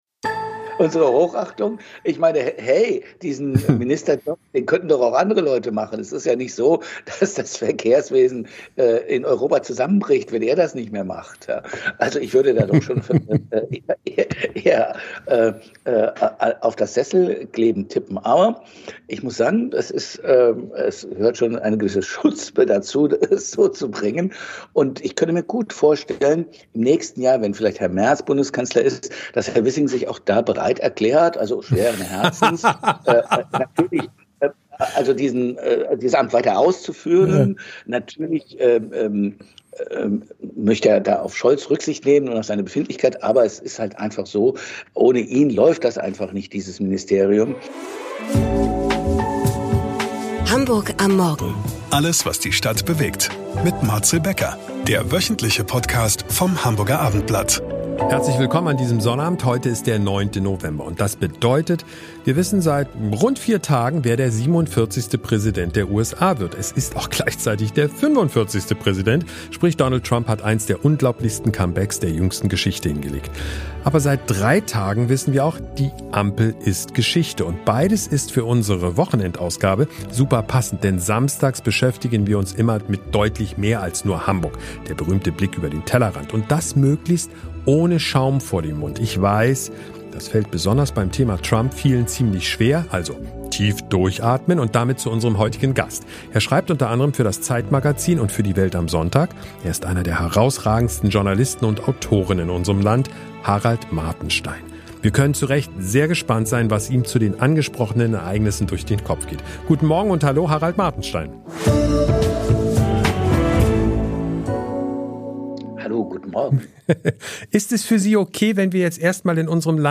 Vom Ampelbruch in Berlin bis zu Donald Trumps Comeback: Martenstein kommentiert die Entwicklungen in seiner typischen Art – scharfzüngig, humorvoll und mit einer Prise Sarkasmus.